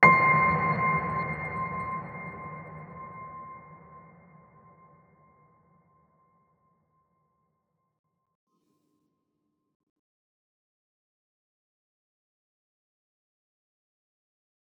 piano5.wav